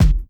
Kick_50.wav